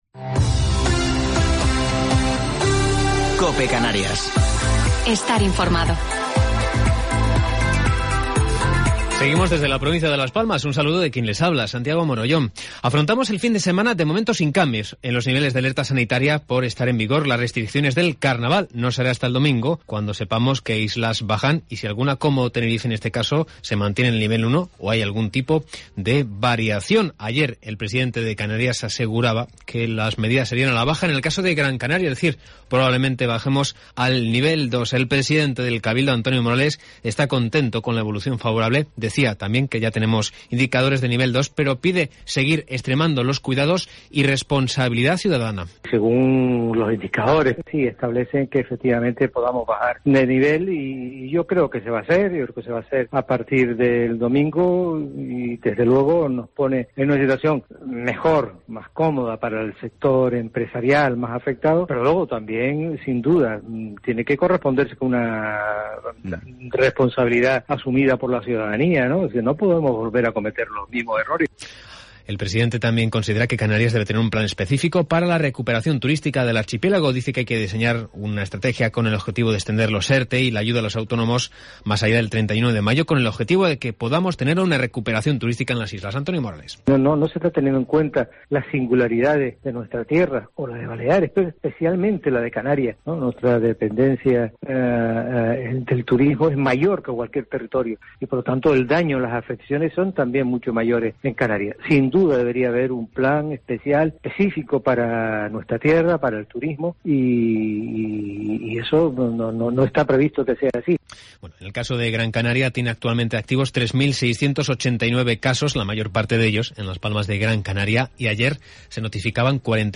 Informativo local 19 de Febrero del 2021